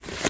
card_slide.ogg